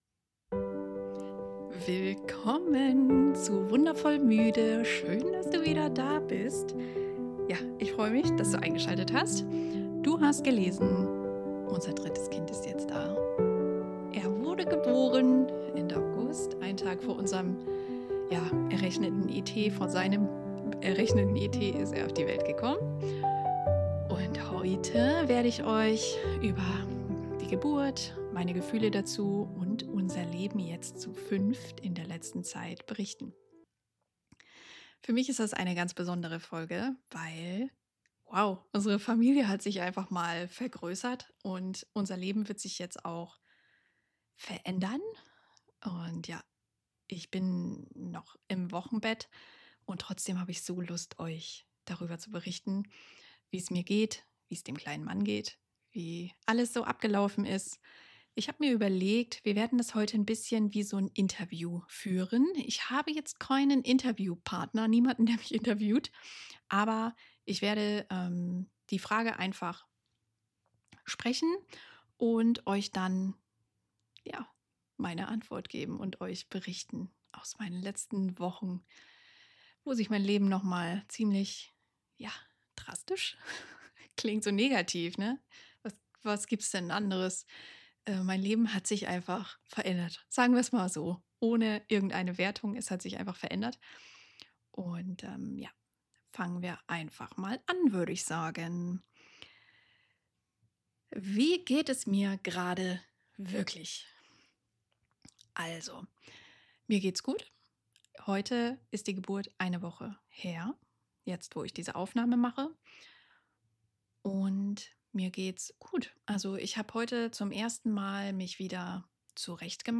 Folge-16-Interview.mp3